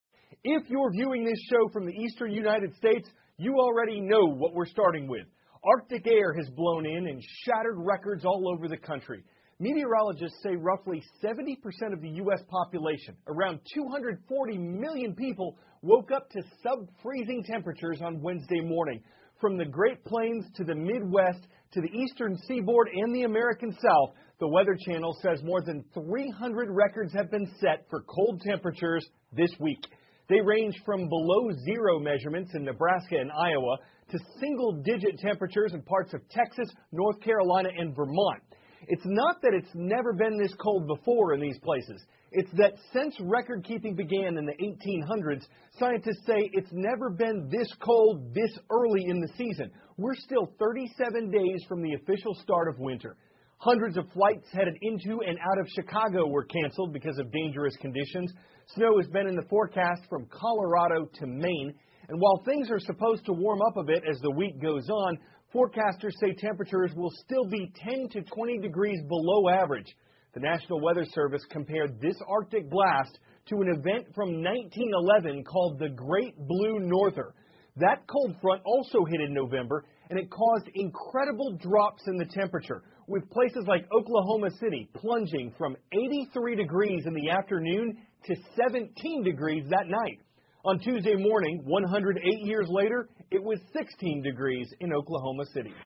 美国有线新闻 CNN 北极冷空气席卷美国 或将创同期最冷纪录 听力文件下载—在线英语听力室